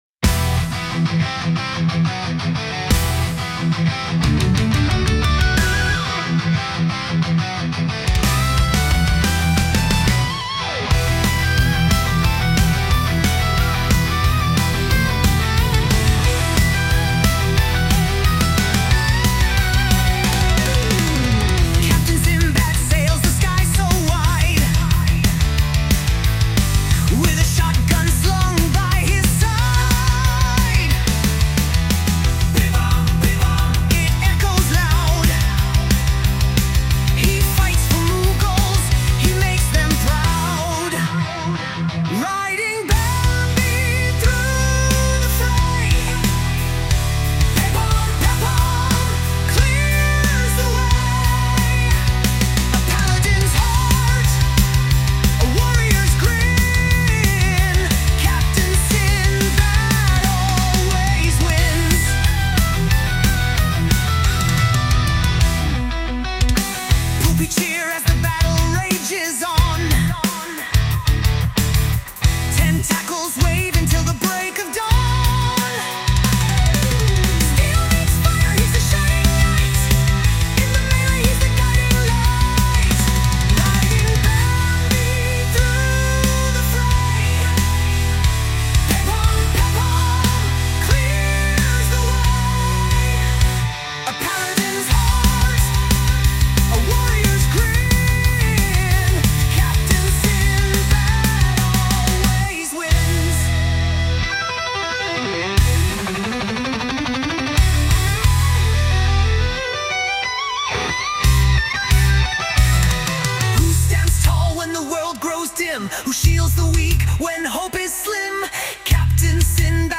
Un vrai hymne de stream, à la fois épique et absurde.
Le ton est léger, les références pleuvent, et chaque ligne est un clin d’œil.